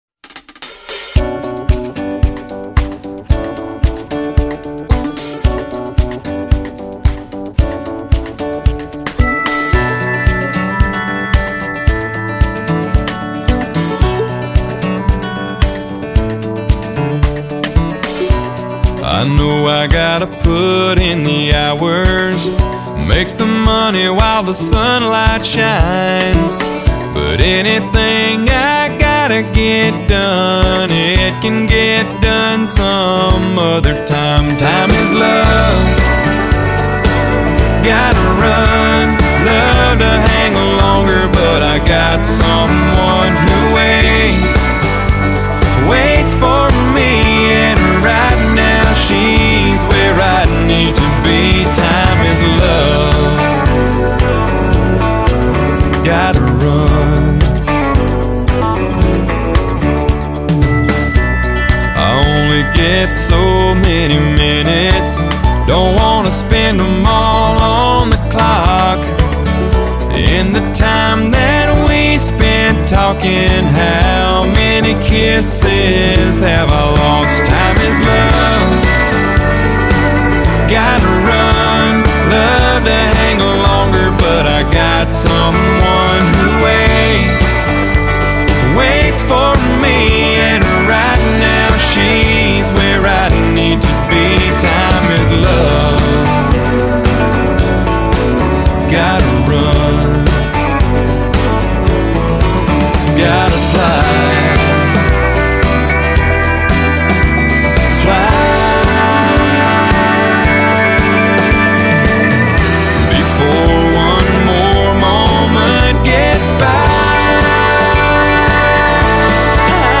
发烧老情歌 纯音乐